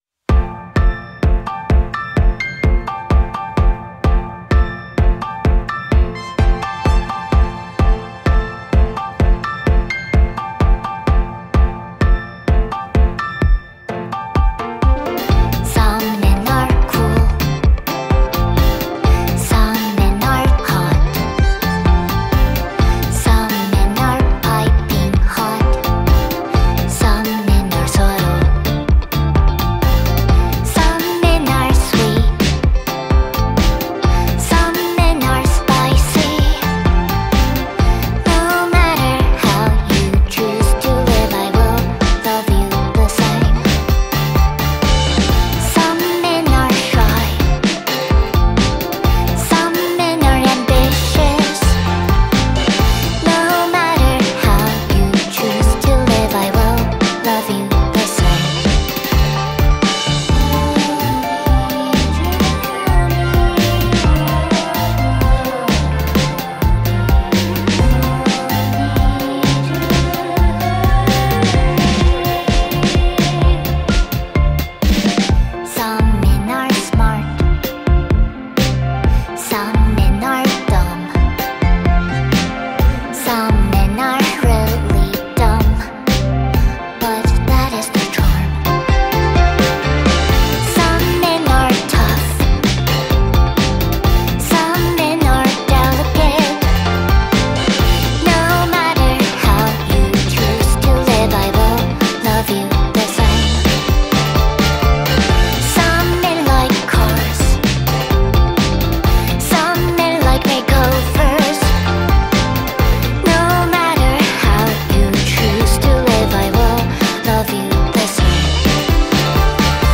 The instrumental kicks ass